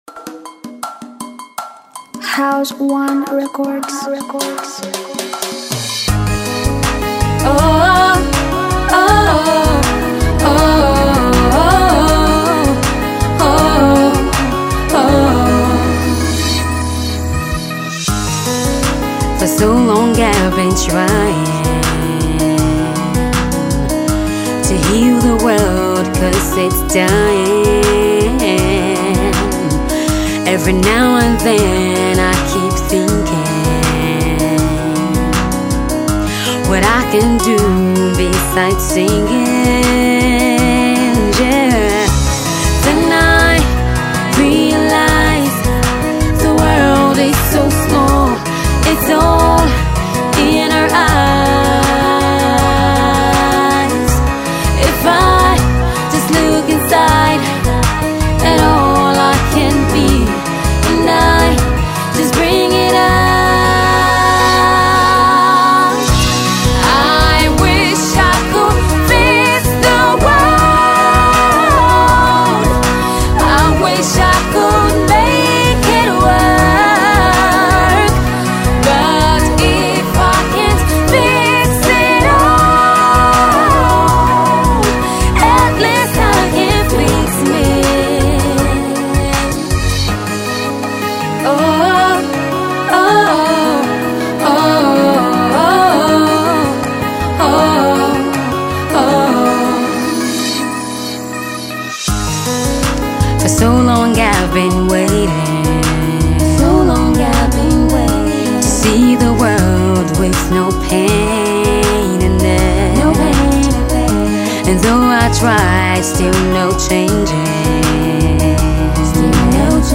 AudioBalladBlues